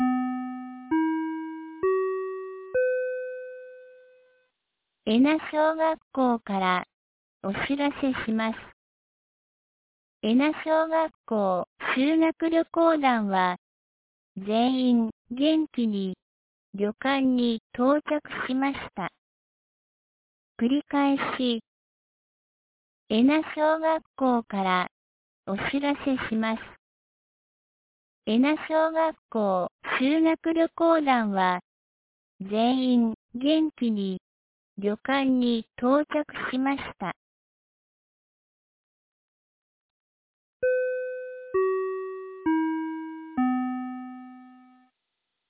2019年10月03日 18時21分に、由良町から衣奈地区、小引地区、戸津井地区、三尾川地区へ放送がありました。